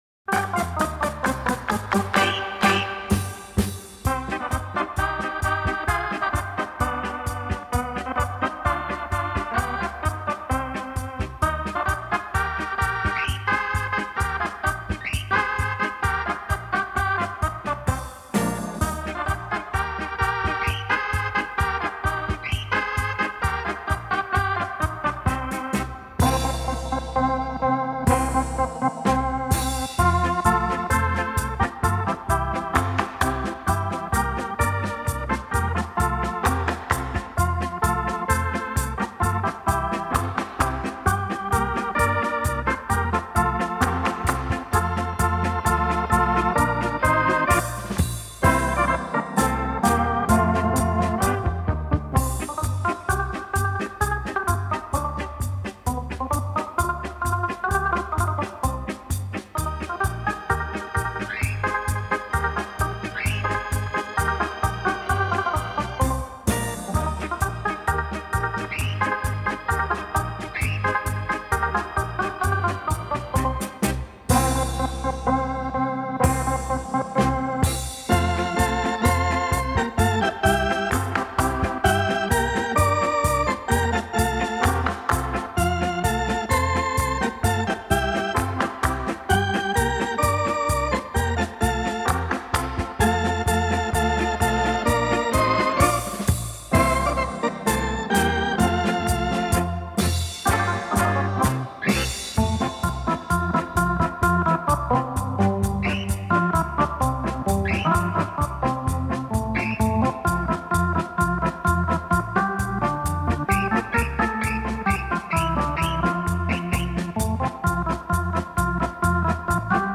且每段音乐由两到三个音乐小节组成，每个小节之间非常连贯，形成一个完整的乐曲